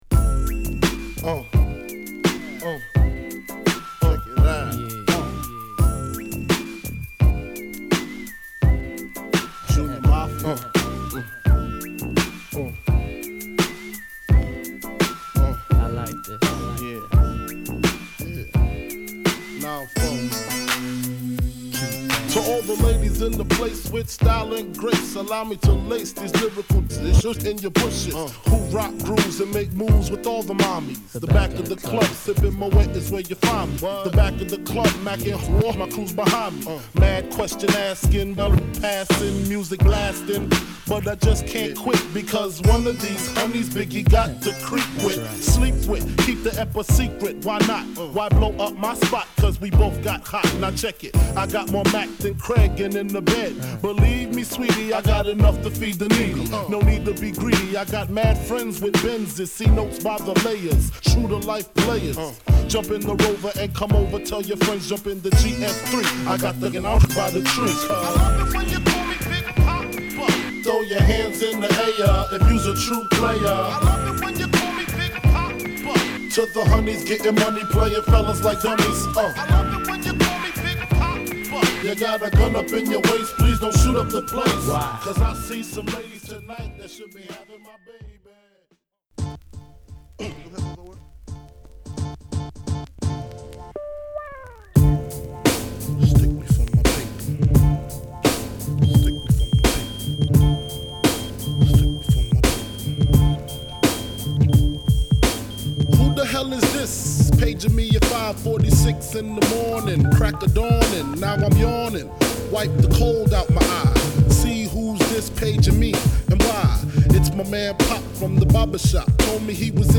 スリリングな空気感を纏った
＊両面イントロにプレス時の凸小有り。小ノイズ入る為ディスカウント。他は綺麗です。